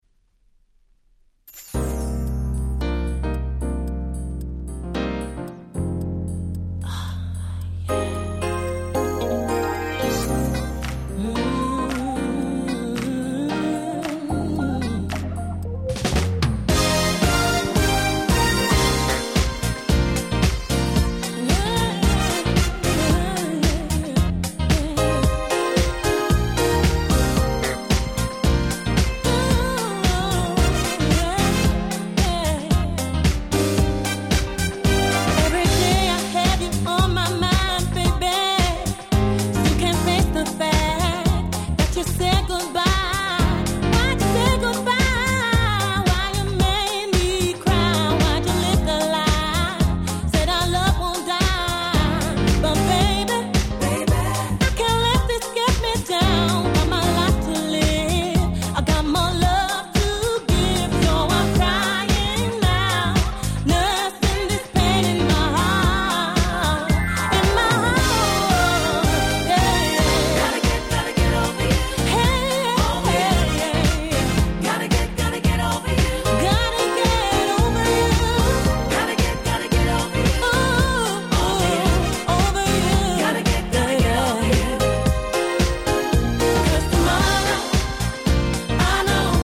02' Nice UK R&B !!